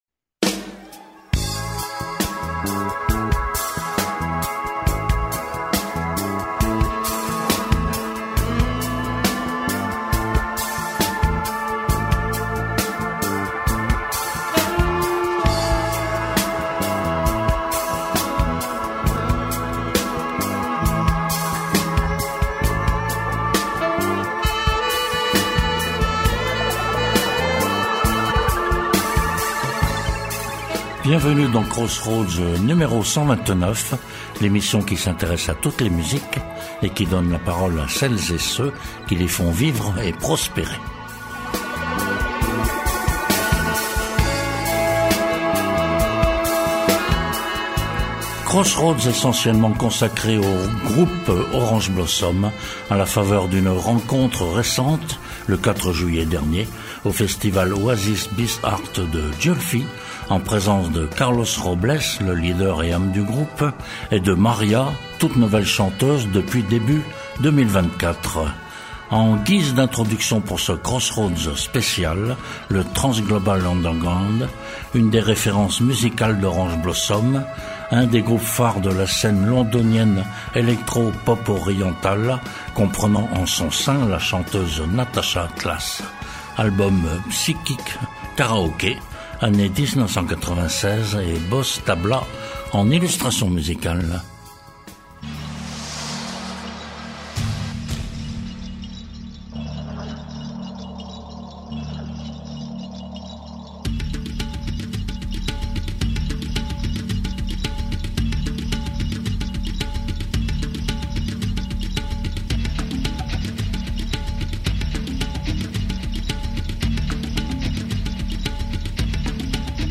Un invité principal au cours de ce Crossroads : Orange Blossom, groupe dont nous suivons les évolutions depuis à peu près 20 ans et que nous rencontrons régulièrement à travers diverses interviews.